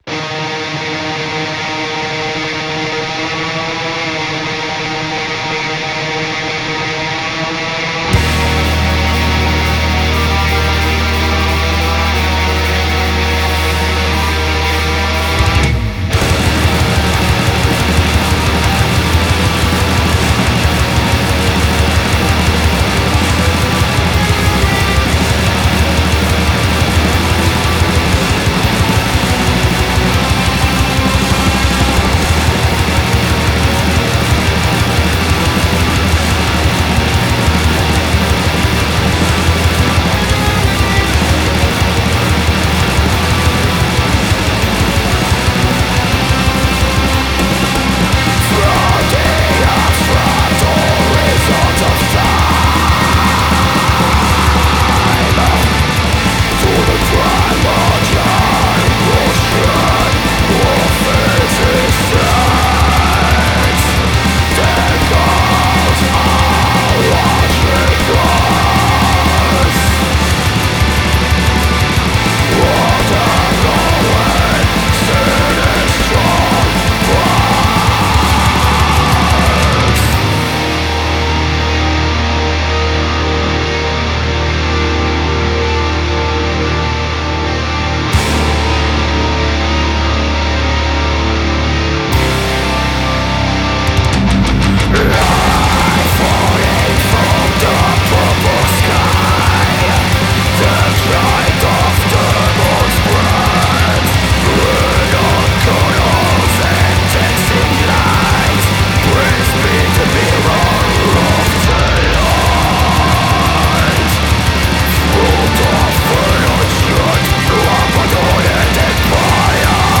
Black Metal band